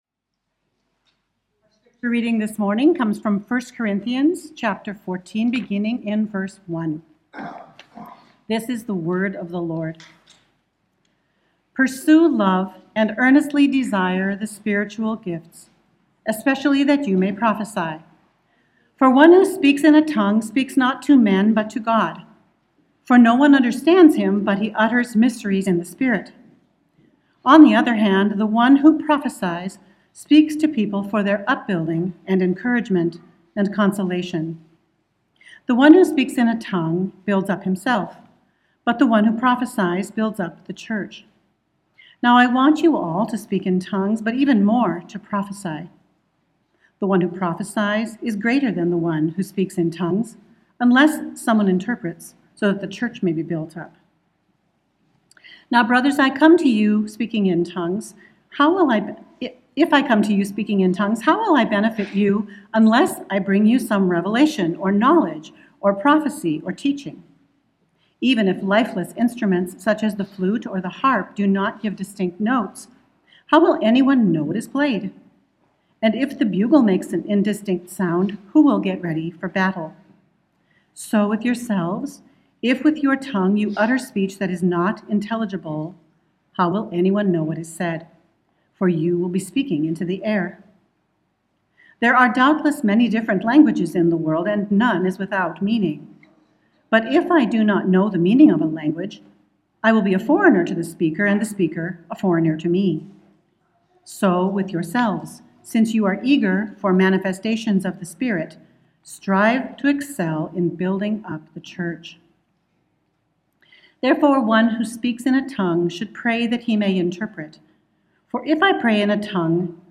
Sermon 1:11:26.m4a